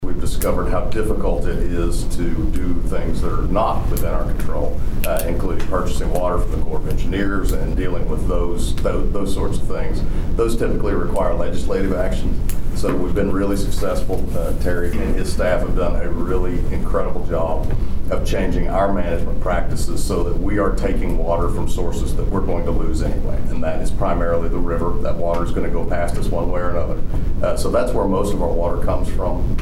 During this week's Bartlesville city council meeting, City Manager Mike Bailey shared a water supply update following the beneficial rains we received during the past week.